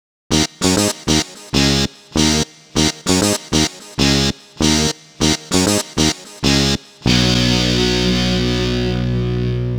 Rock Star - Power Guitar 03.wav